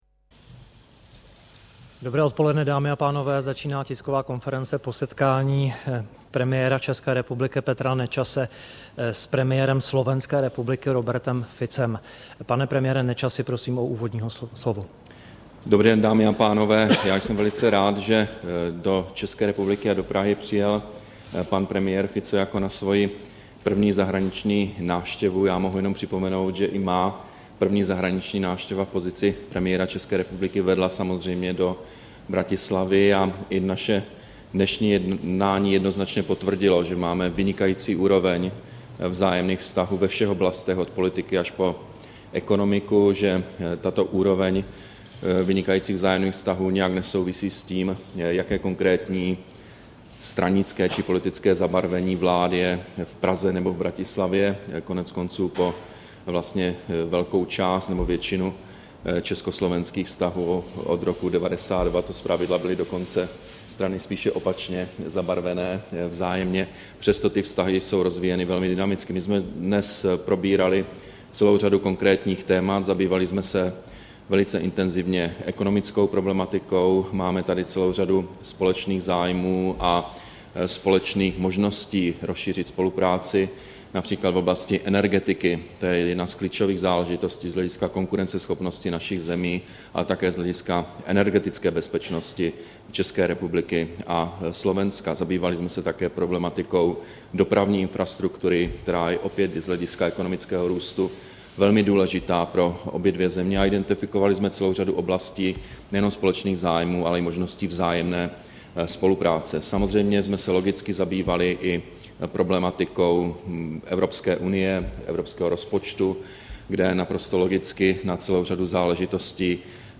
Tisková konference po jednání předsedy vlády Petra Nečase s předsedou vlády Slovenské republiky Robertem Ficem, 20. dubna 2012